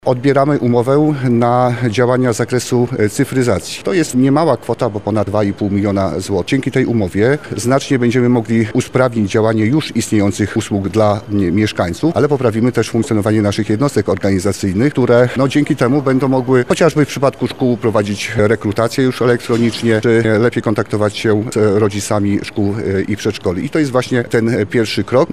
– mówi Wojciech Żukowski, Burmistrz Tomaszowa Lubelskiego.